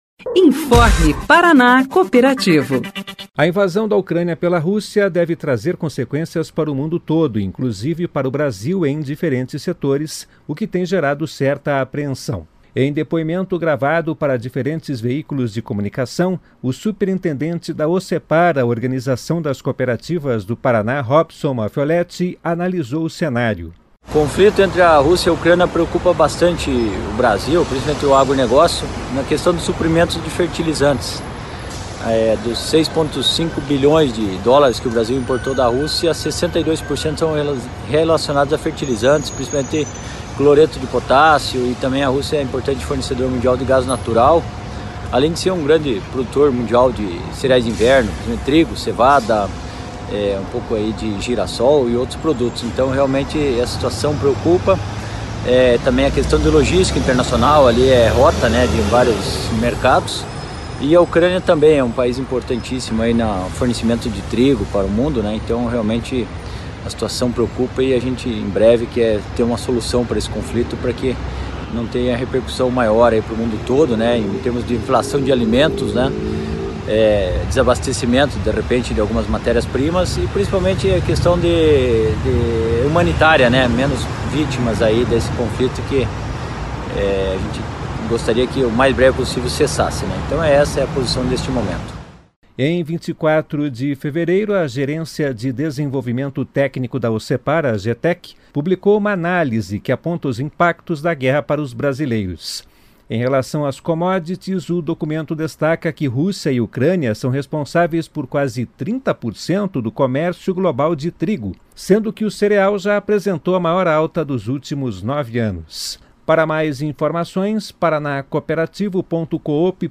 Em depoimento gravado para diferentes veículos de comunicação